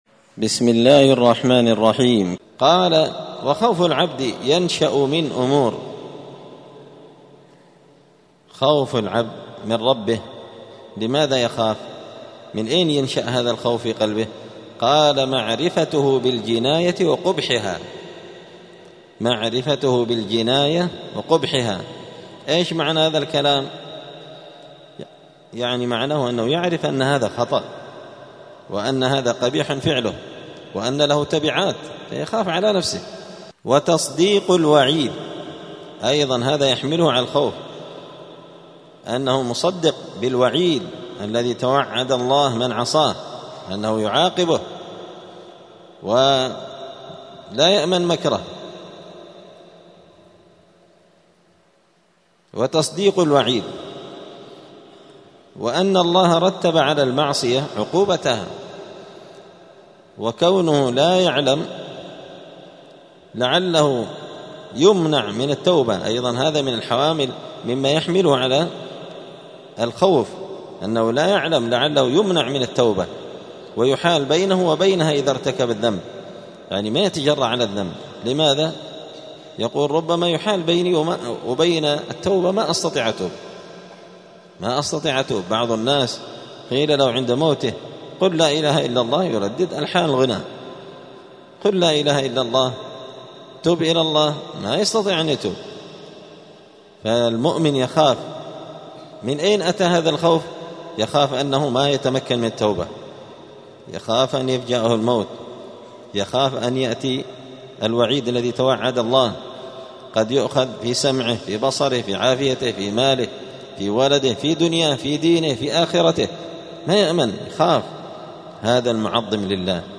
دار الحديث السلفية بمسجد الفرقان قشن المهرة اليمن
*الدرس الخامس والتسعون (95) {تابع لباب قول الله تعالى أفأمنوا مكر الله فلا يأمن مكر الله إلا القوم الخاسرون}*